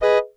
Default dry sound: